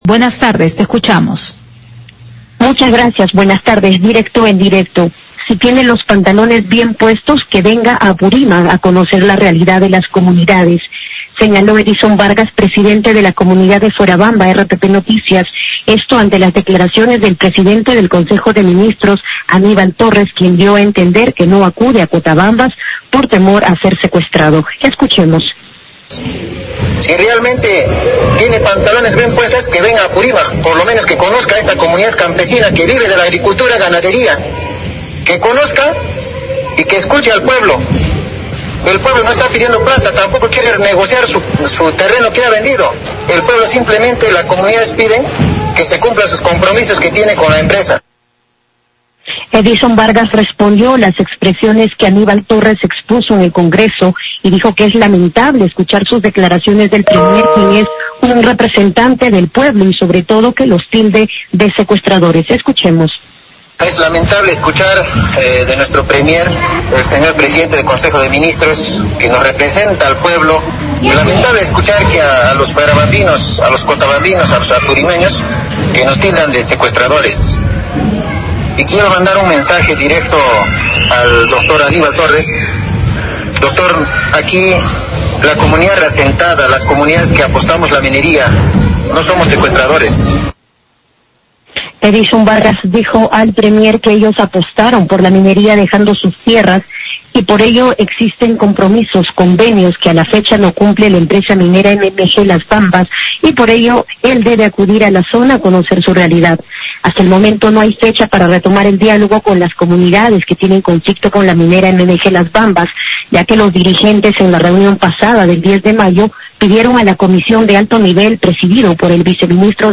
Despacho en vivo